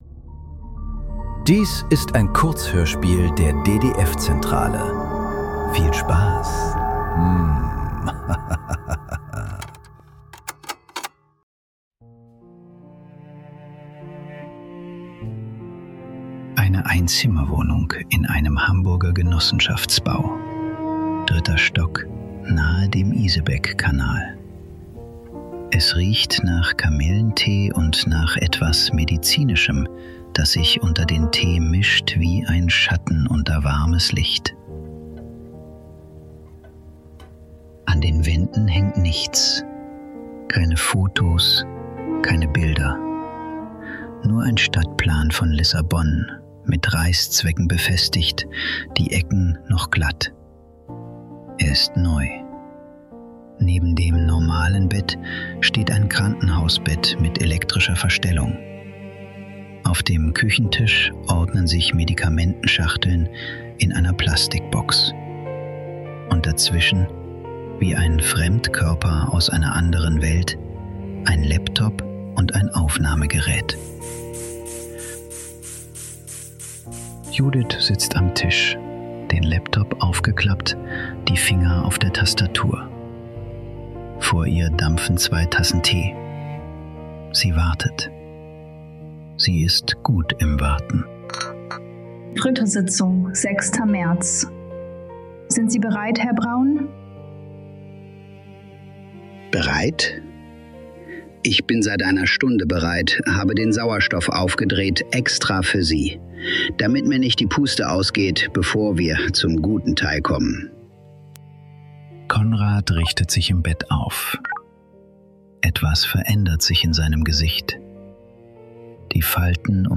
Kurzhörspiele. Leise.